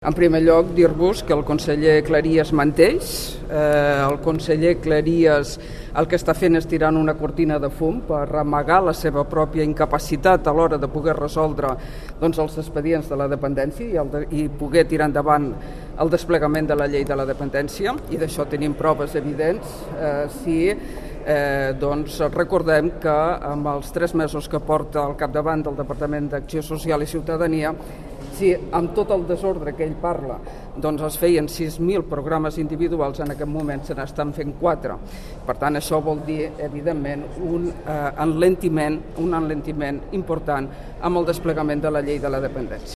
En un cara a cara a Catalunya Ràdio, Capdevila ha acusat Cleries de mentir i de posar ‘una cortina de fum per amagar la seva mala gestió a l’hora de resoldre els expedients de la llei de dependència’ (